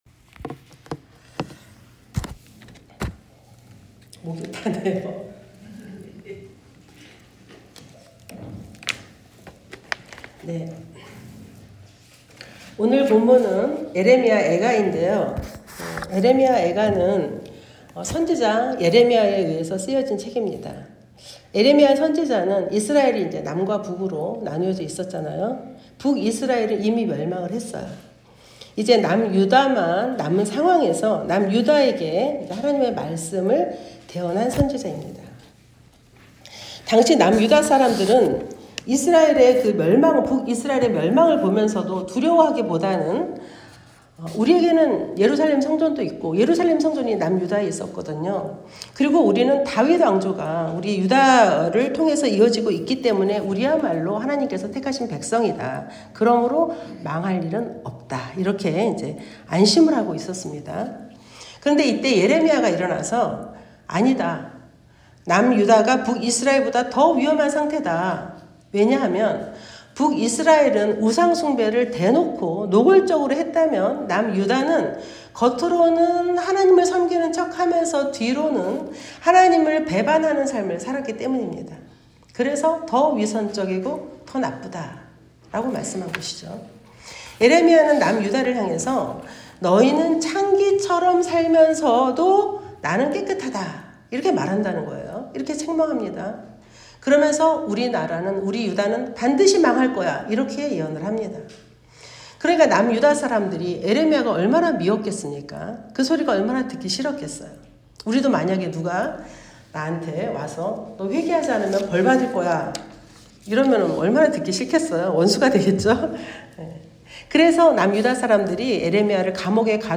절망 속에서 피어난 희망 ( 애3:22-23 ) 말씀